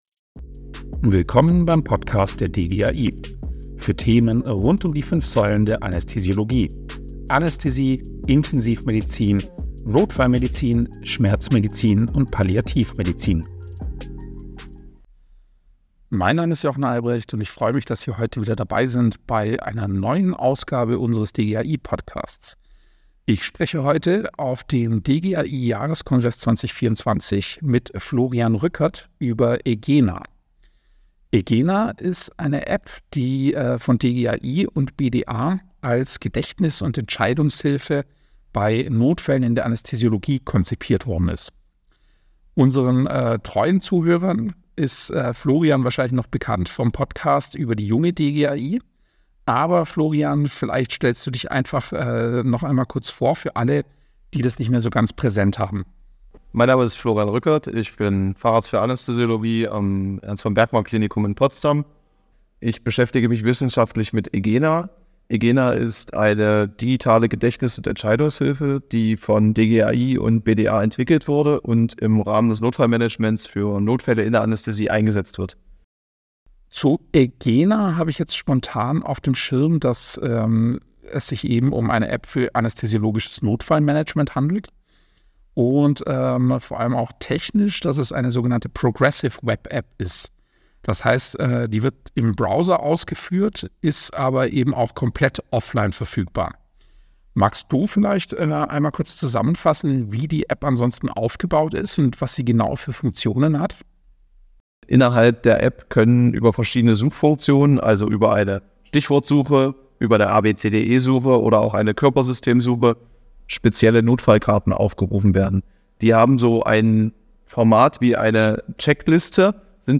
DGAI-Podcast: Die eGENA-App für Notfälle in der Anästhesie: Ein Gespräch